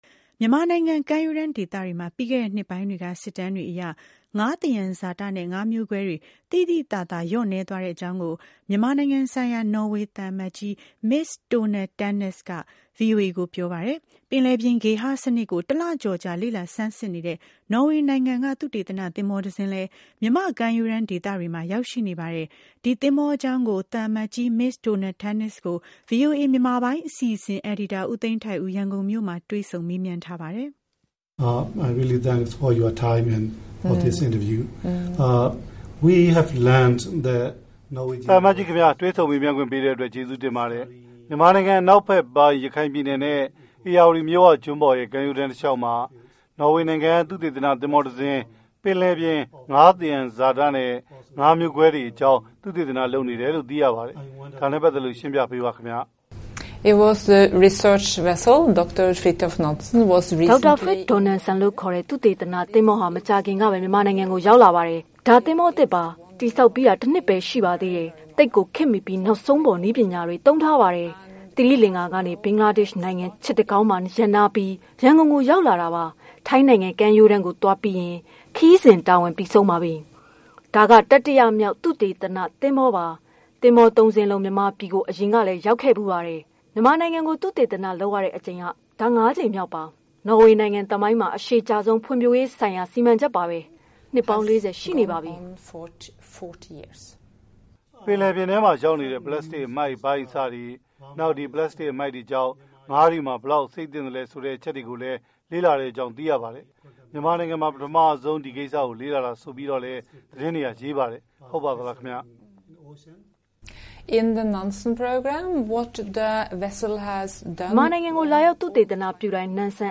ရန်ကုန်မြို့မှာ တွေ့ဆုံမေးမြန်းထားတာပါ။